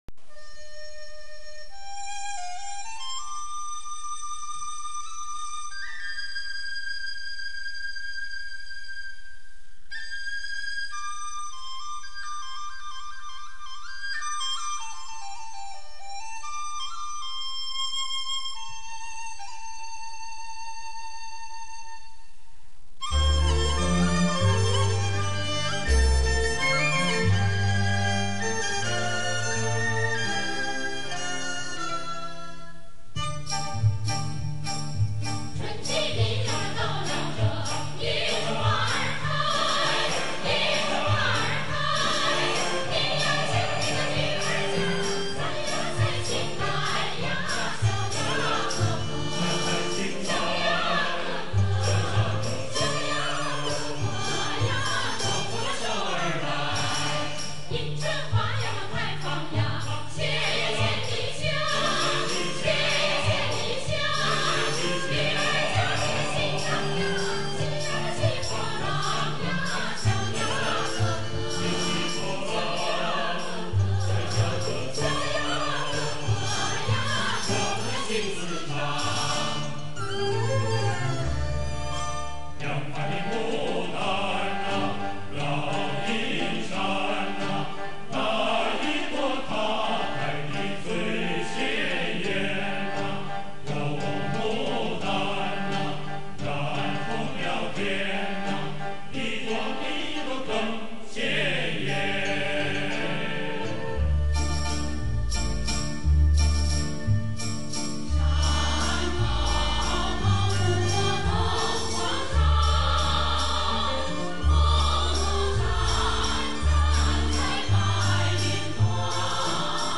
青海花儿